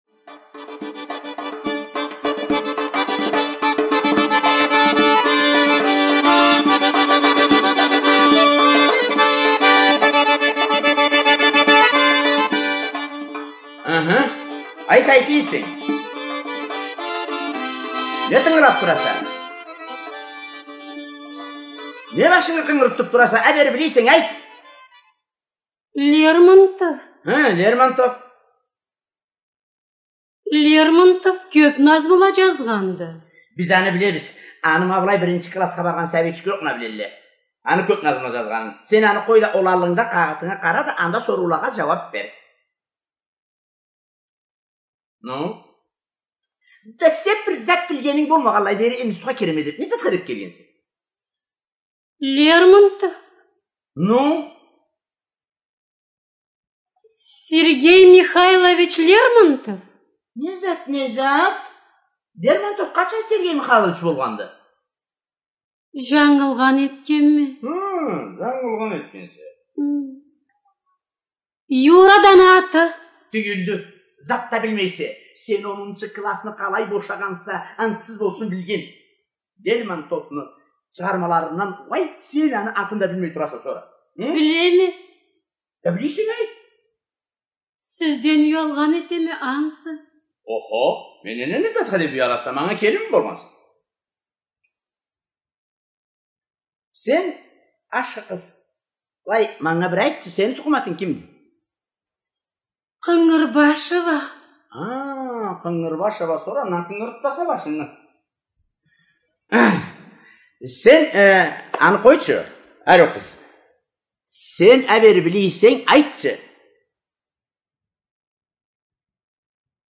ЖанрРадиоспектакль на языках народов России